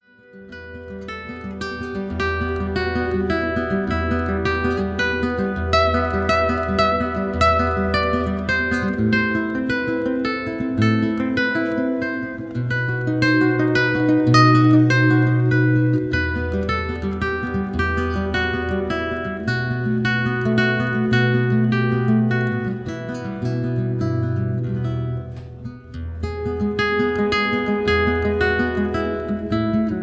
Solo Guitar Standards
Soothing and Relaxing Guitar Music